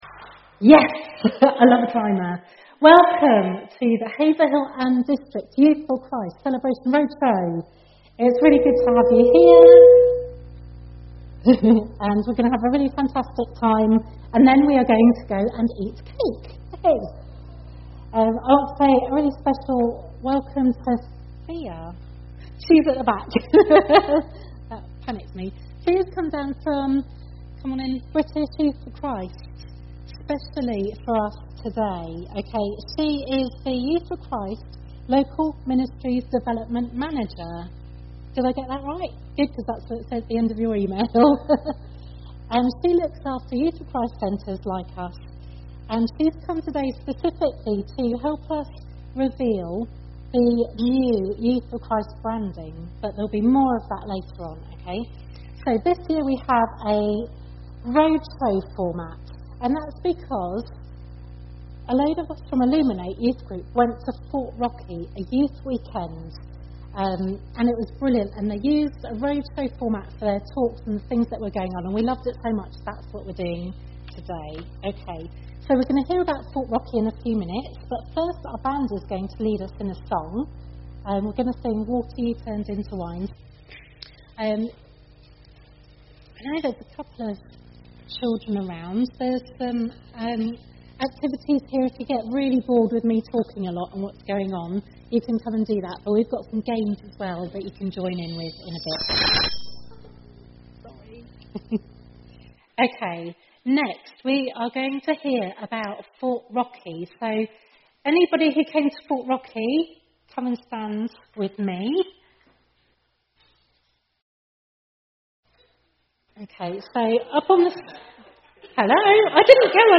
the young people take a major part in leading the event, including providing the music.